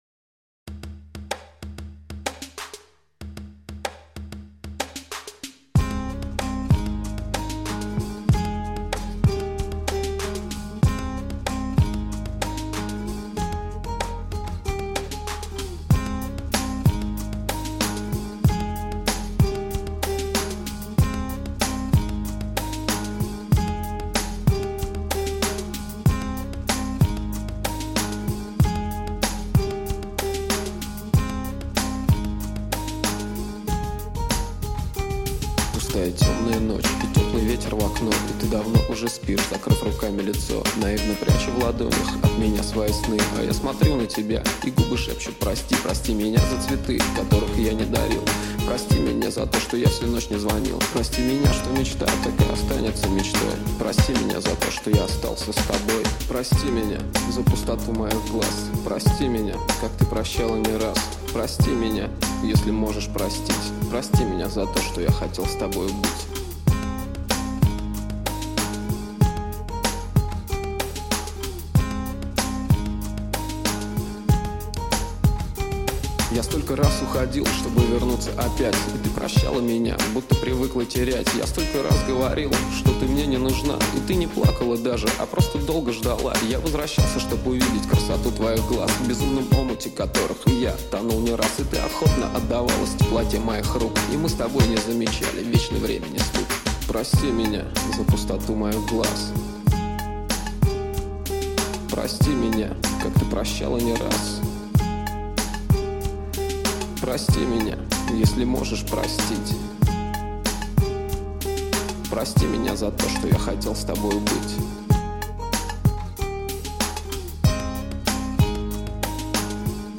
основанный на сэмпле